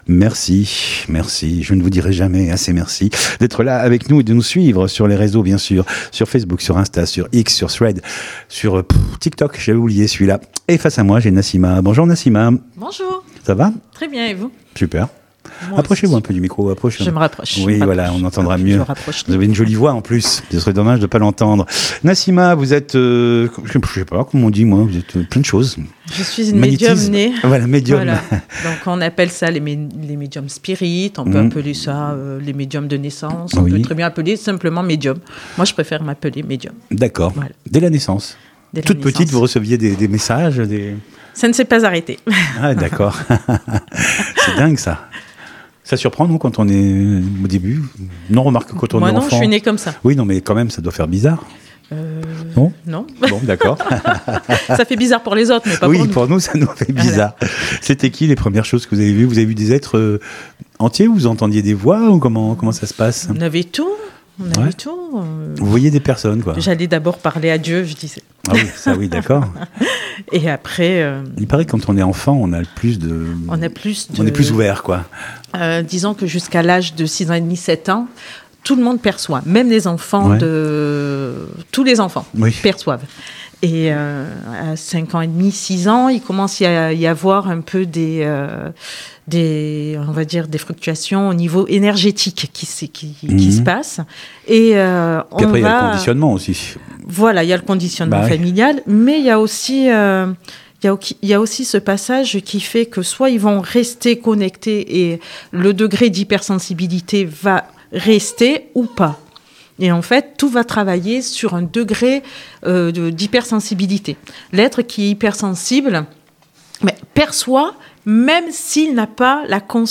Bien être/Santé Interviews courtes